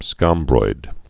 (skŏmbroid)